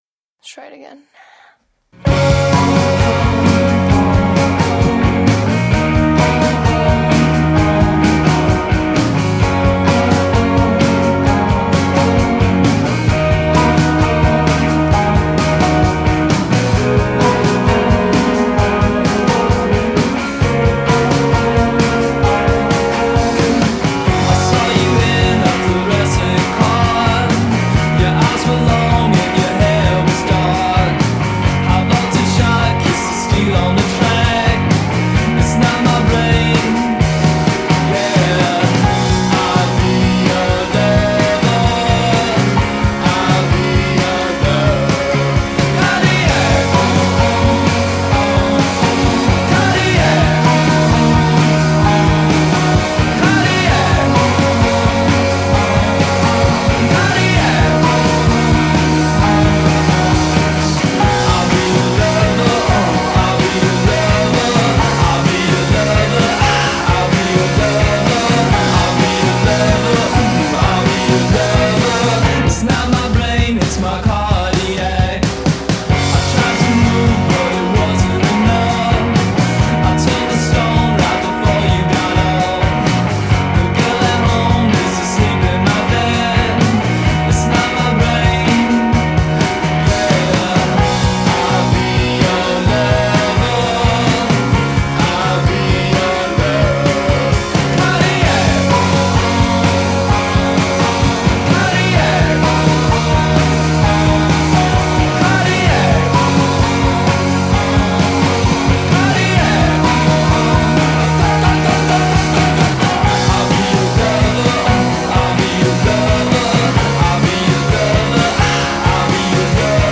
“dark garage” music
Vocals and Guitar
Bass
Drums